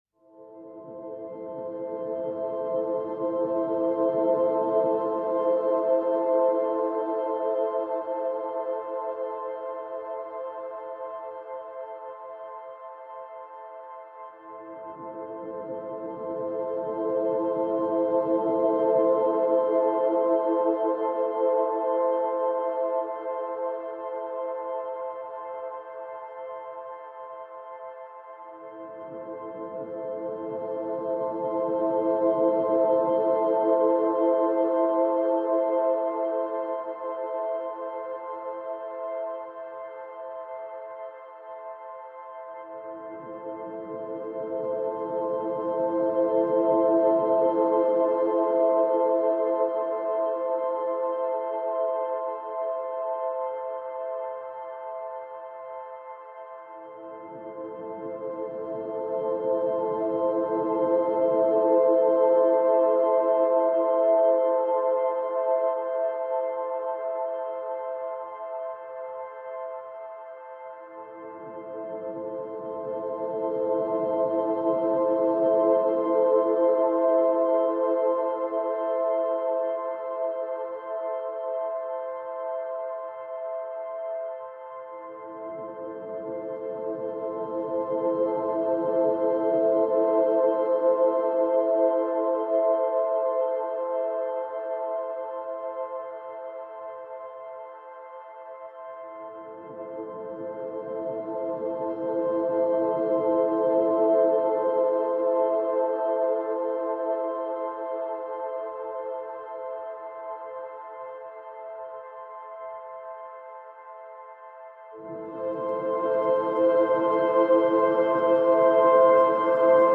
Light Drone Version